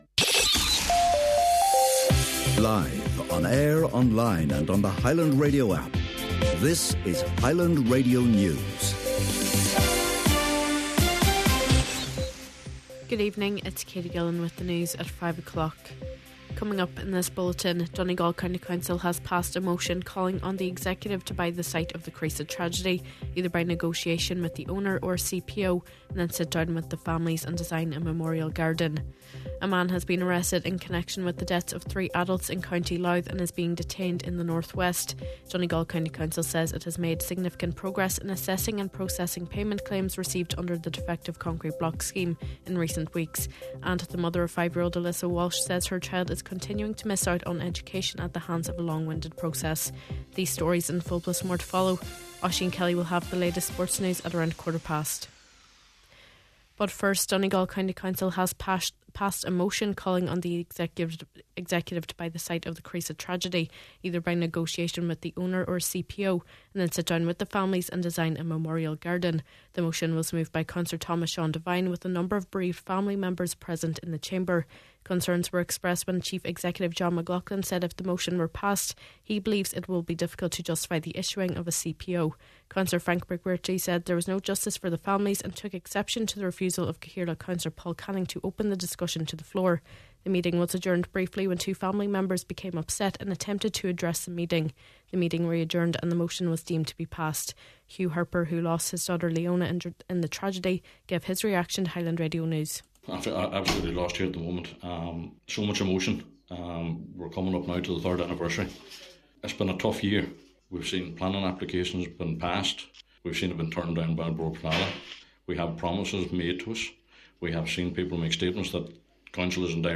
Main Evening News, Sport and Obituary Notices – Monday September 29th